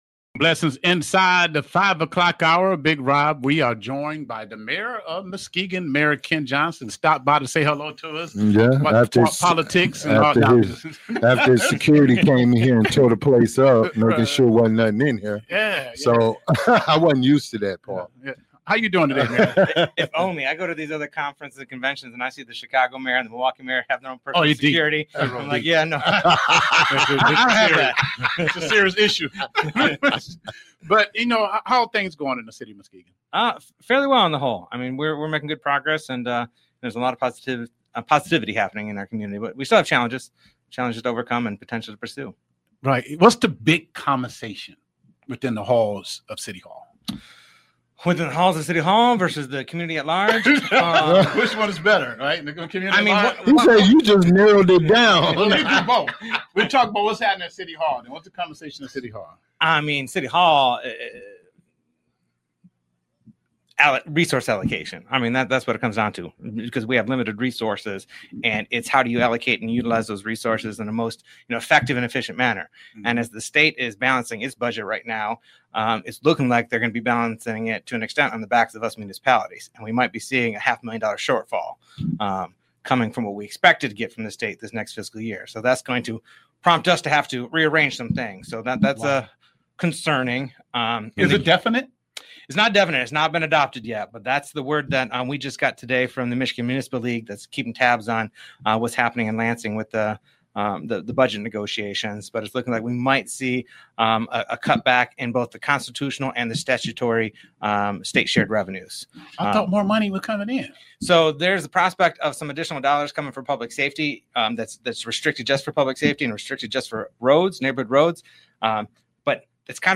Interview with Muskegon Mayor Ken Johnson